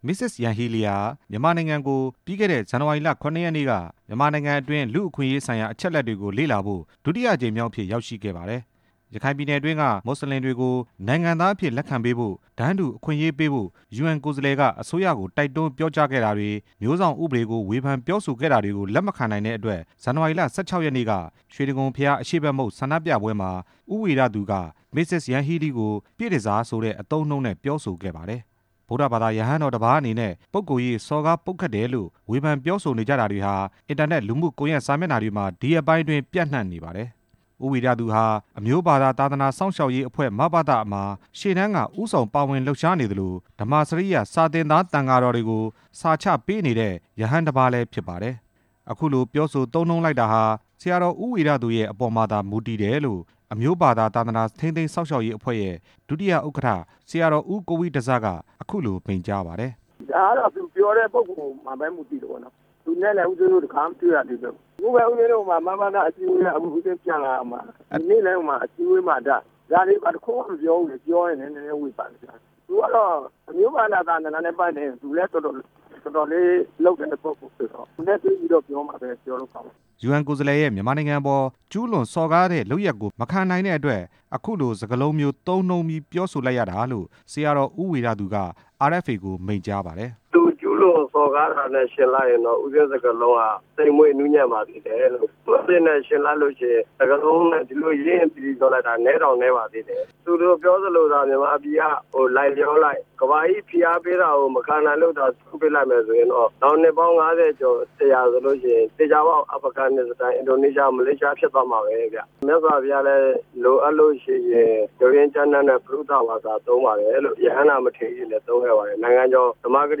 ယူအန်ကိုယ်စားလှယ်ရဲ့ မြန်မာနိုင်ငံအပေါ်ကျူးလွန်စော်ကားတဲ့လုပ်ရပ်ကို မခံနိုင်တဲ့အတွက် အခုလို စကား လုံးမျိုး သုံးပြီးပြောလိုက်တာလို့ ဆရာတော်ဦးဝီရသူက RFA ကို မိန့်ကြားပါတယ်။